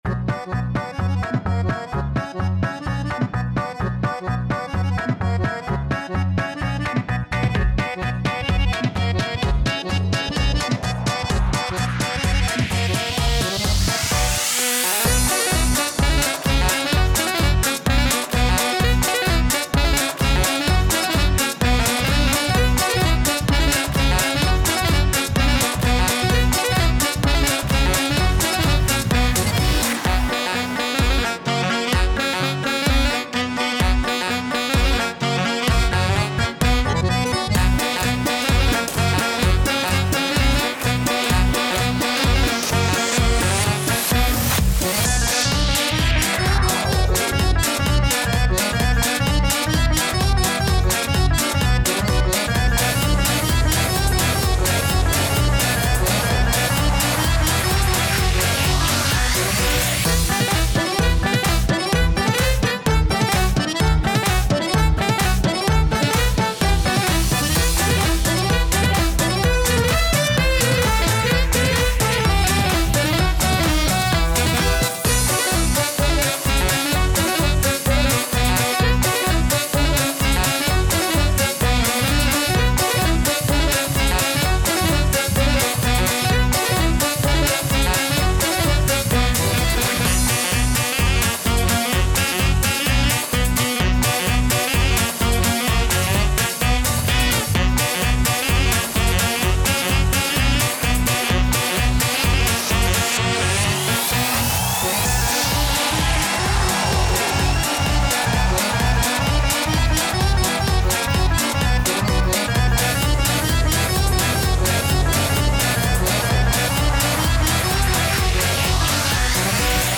EDM Electro Swing おしゃれ かっこいい 民族音楽 コメント